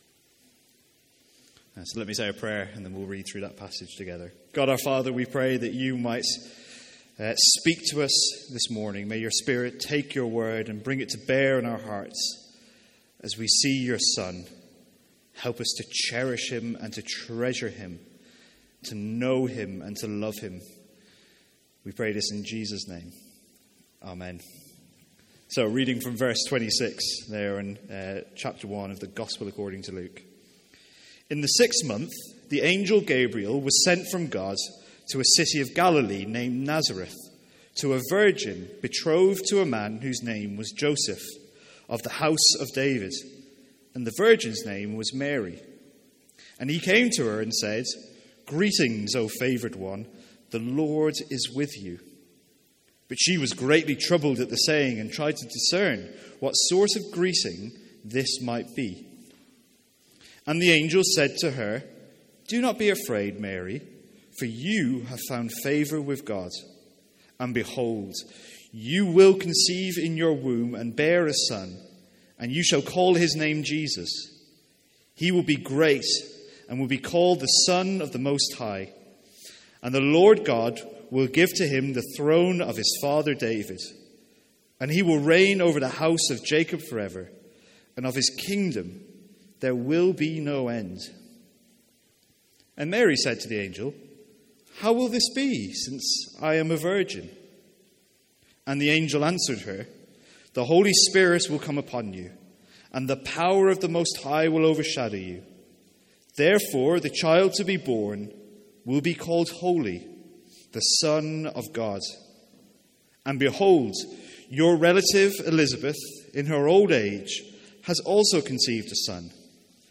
From our morning Christmas sermons.